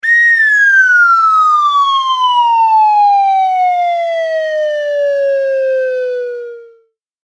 演出 （102件）
スライドホイッスル下降3.mp3